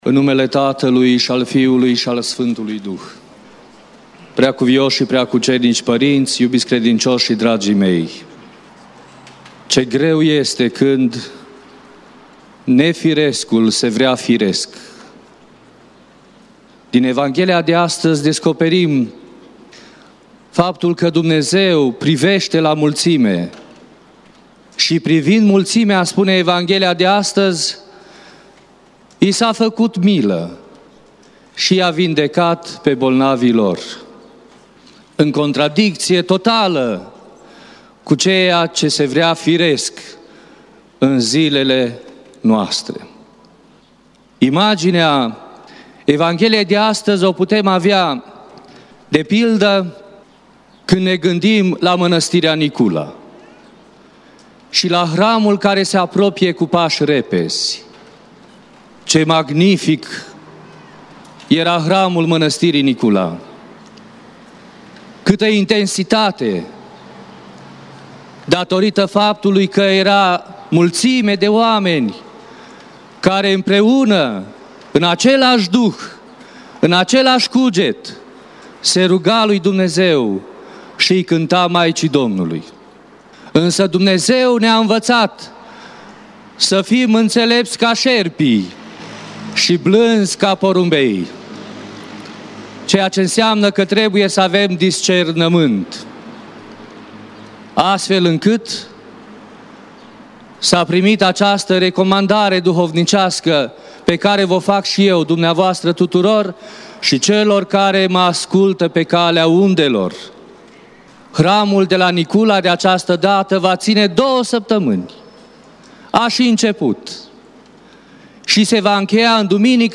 Predică la Duminica a 8-a după Rusalii